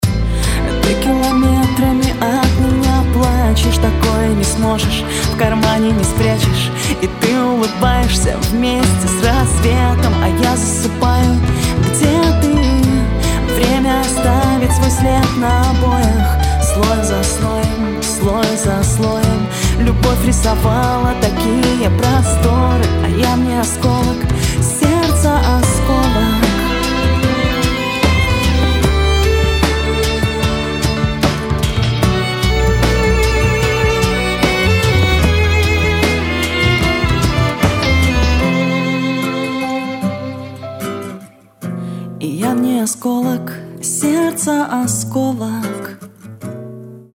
• Качество: 192, Stereo
Лиричная премьера